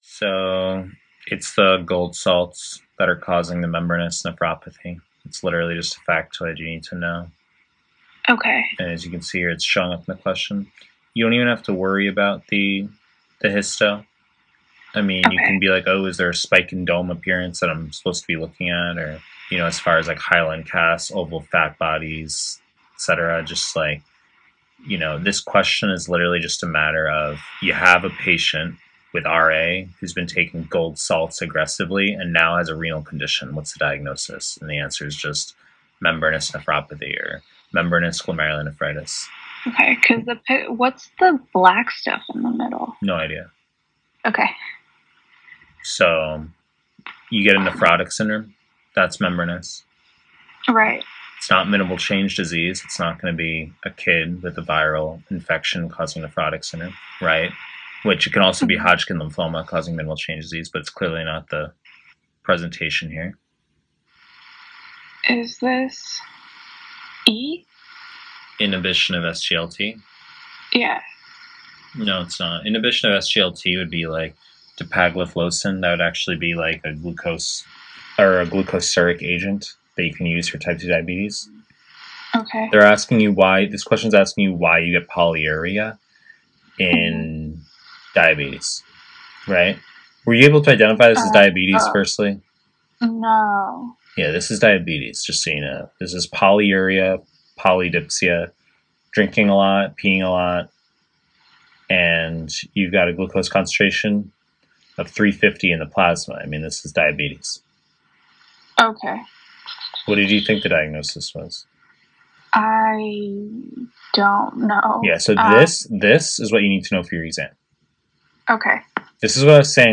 Pre-recorded lectures / Renal